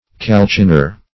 Calciner \Cal*cin"er\, n. One who, or that which, calcines.